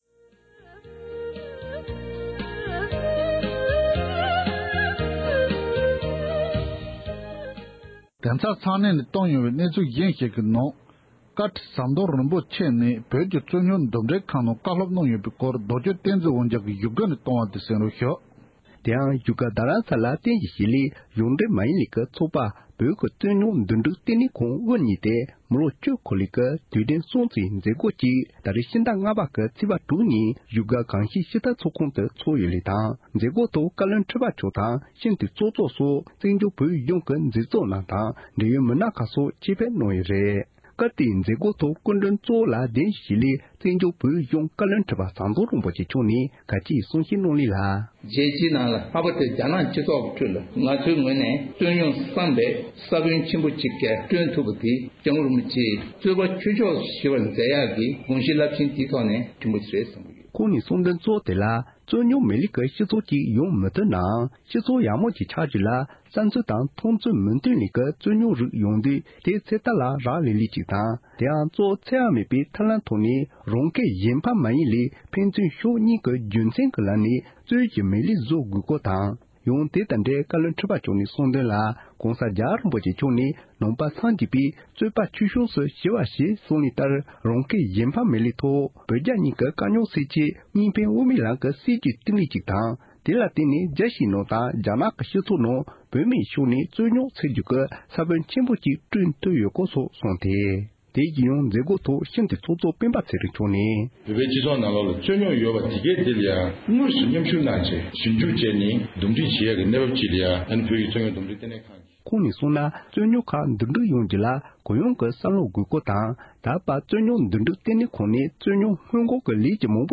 རྩོད་རྙོག་འདུམ་སྒྲིག་ལྟེ་གནས་ཁང་དབུ་བརྙེད་ནས་ལོ་ངོ་༡༠འཁོར་བའི་དུས་དྲན་མཛད་སྒོའི་ཐོག་བཀའ་བློན་ཁྲི་པ་མཆོག་ནས་བཀའ་སློབ།
སྒྲ་ལྡན་གསར་འགྱུར།